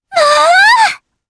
Frey-Vox_Happy4_jp_b.wav